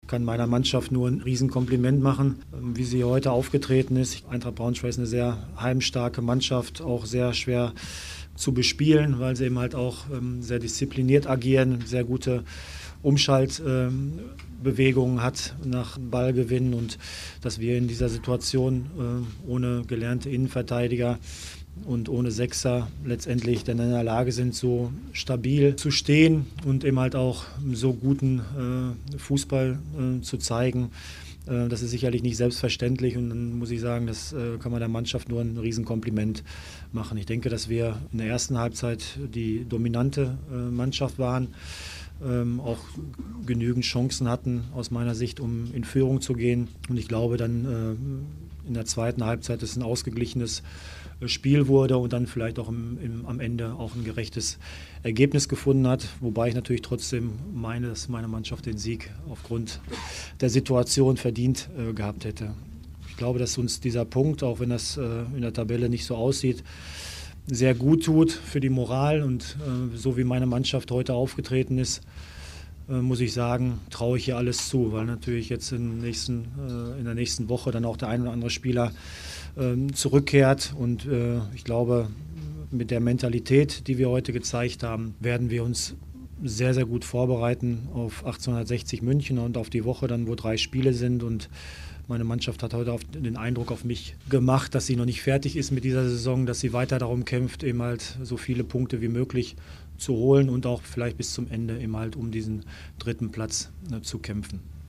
Audiokommentar
Chef-Trainer Roger Schmidt zum Spiel